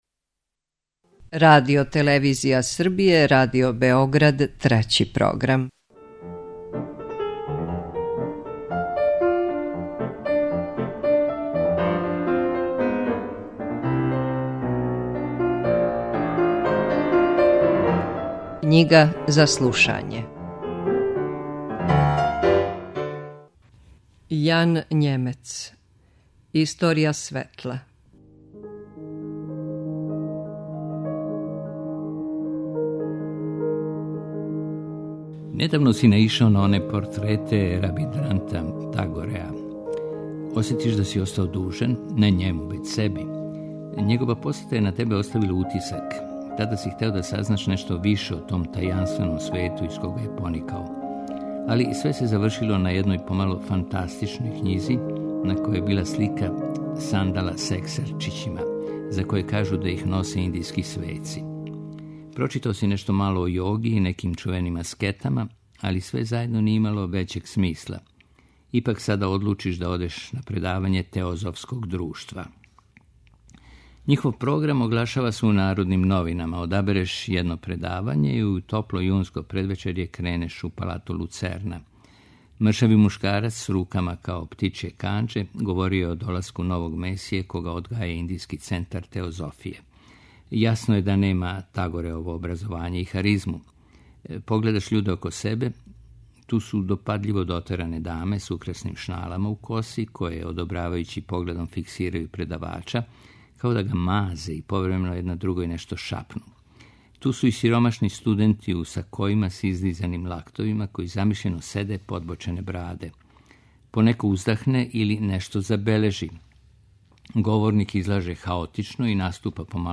Knjiga za slušanje